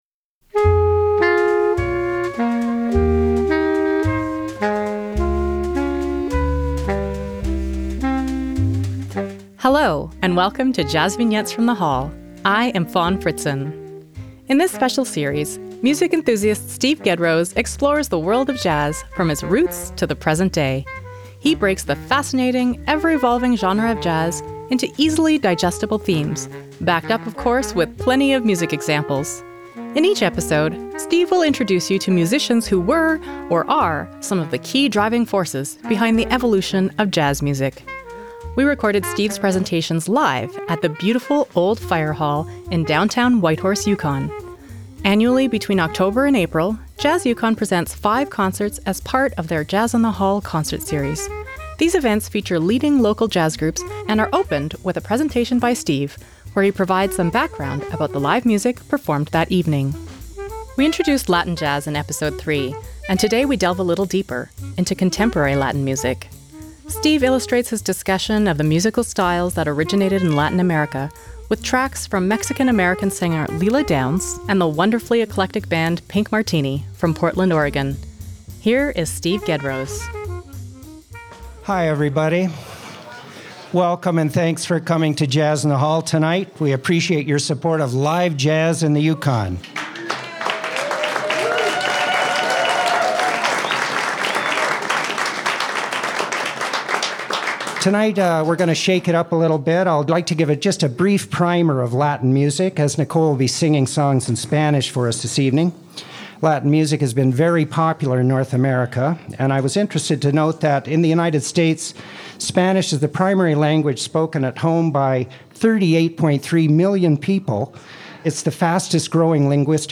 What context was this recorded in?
jvfth13ContemporaryLatinMusic.mp3 57,847k 256kbps Stereo Comments